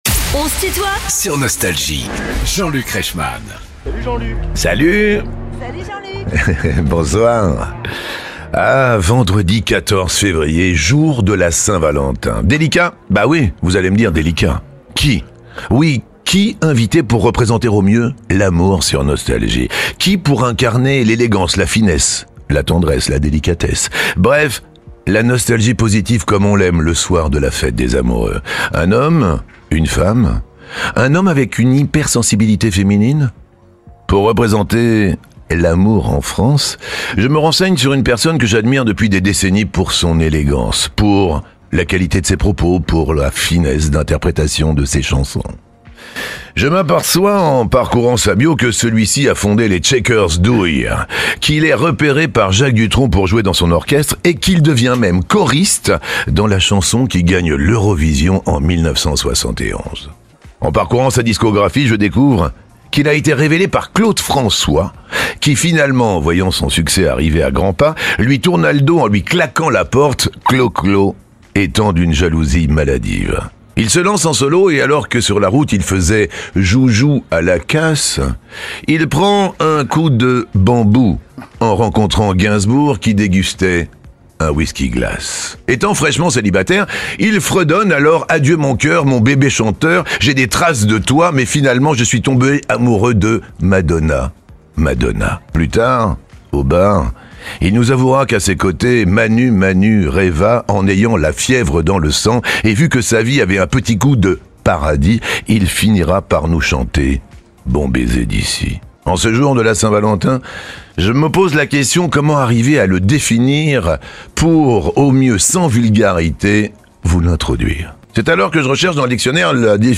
Alain Chamfort est l'invité de "On se tutoie ?..." avec Jean-Luc Reichmann